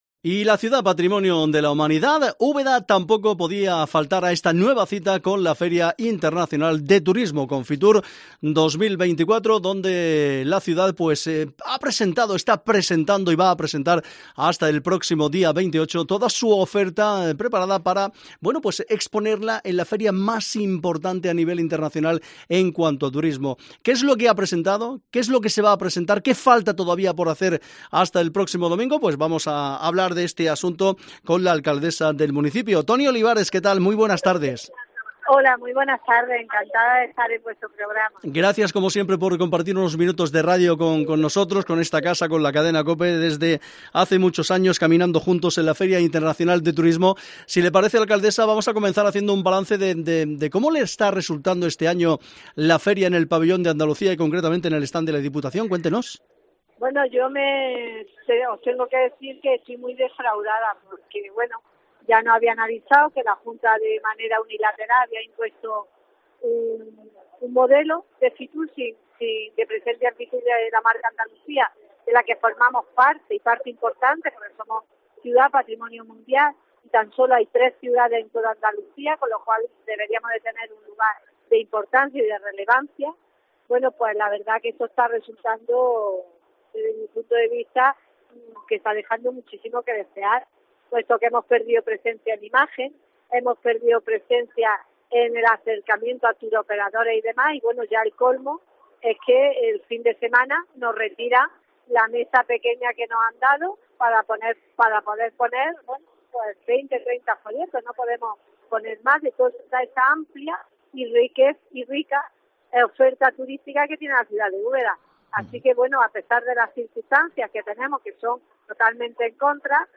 Charlamos en FITUR con Antonia Olivares, alcaldesa de Úbeda